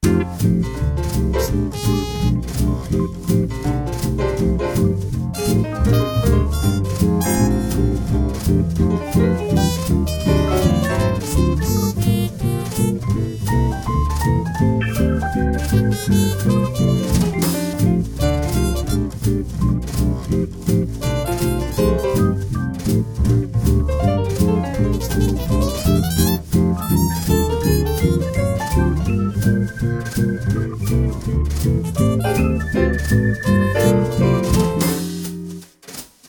trompeta
swing
piano
batería
melodía
Sonidos: Música